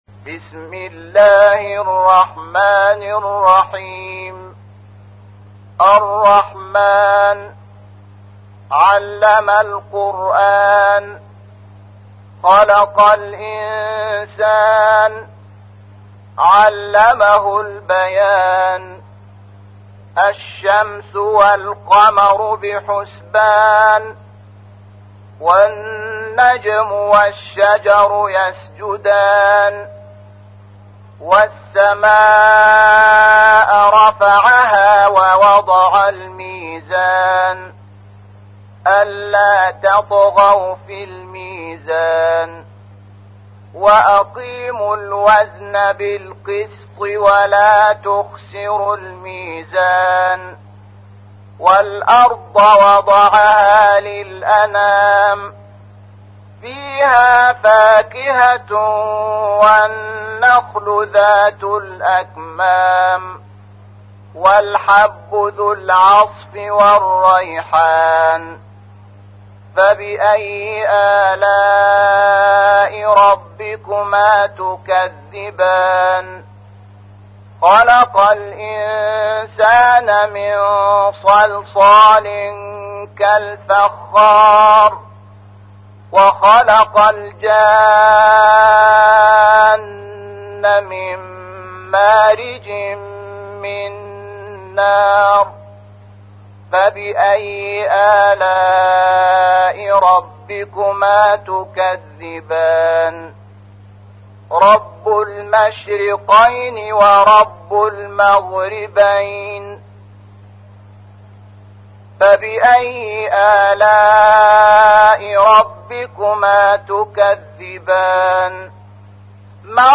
تحميل : 55. سورة الرحمن / القارئ شحات محمد انور / القرآن الكريم / موقع يا حسين